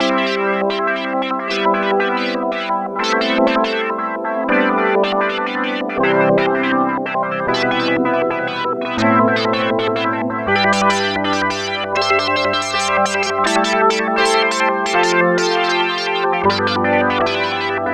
Track 16 - Bubble Synth.wav